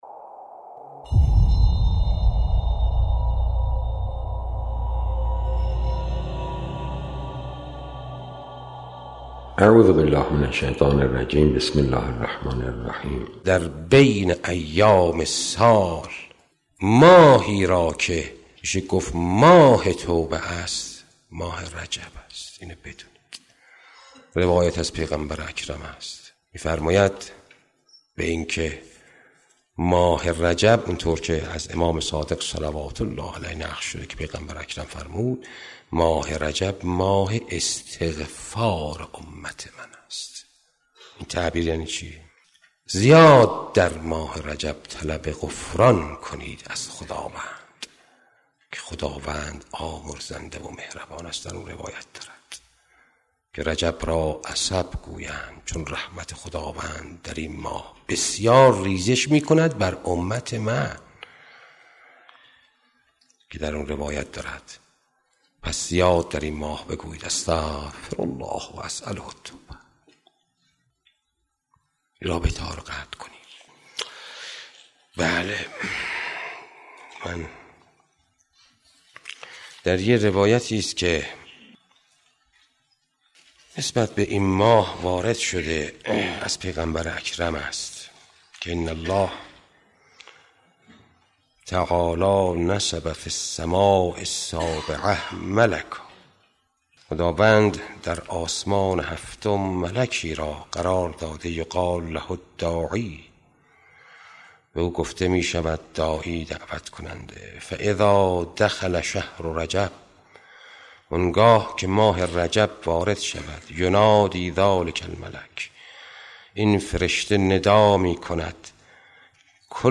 توصیه های حاج آقا مجتبی تهرانی درباره ماه رجب